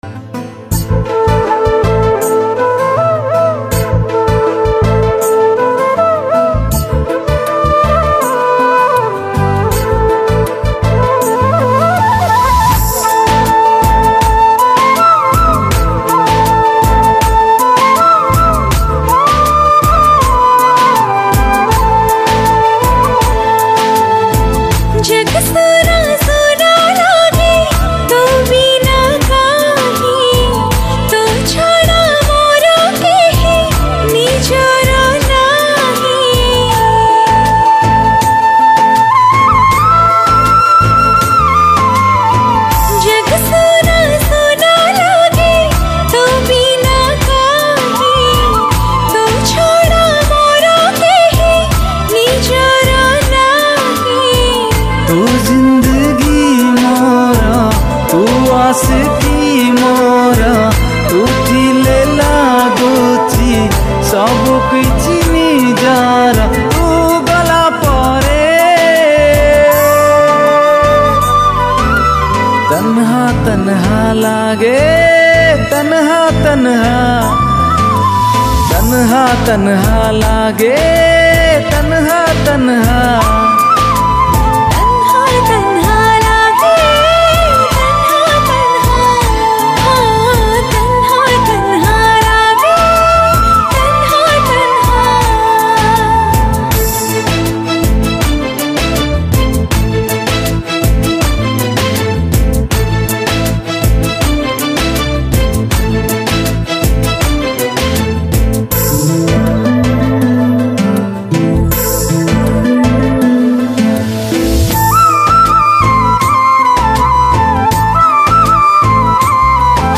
Soft Romantic